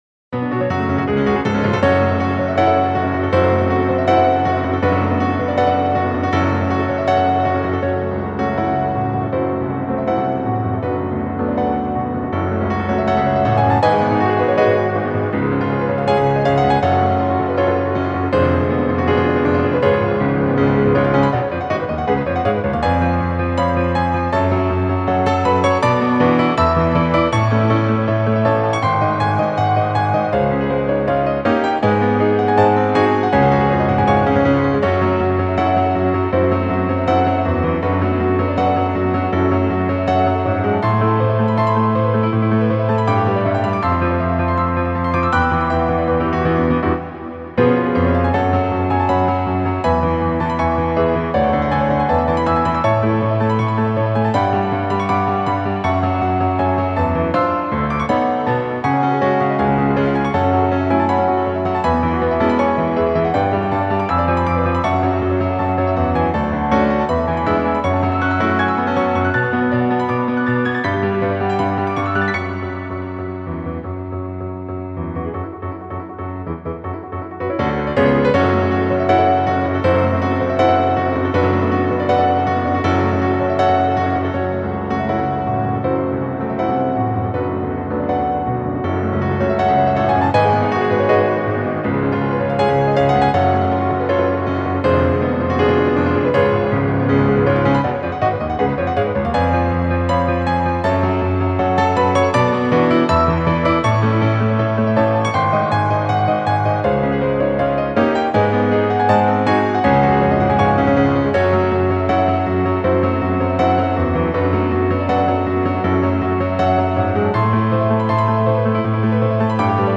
ピアノアレンジ